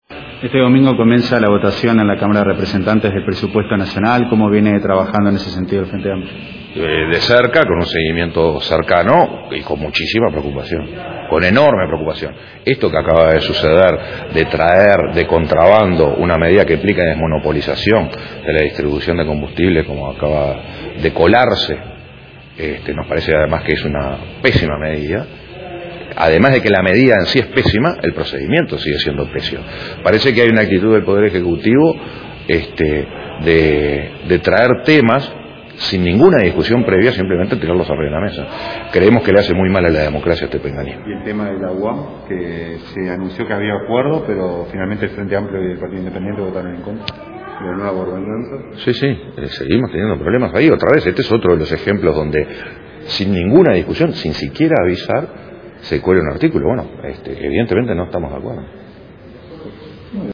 En declaraciones a la prensa, el presidente del Frente Amplio, Javier Miranda, se refirió a la Ley de Presupuesto que inicia discusión en la Cámara de Diputados este domingo.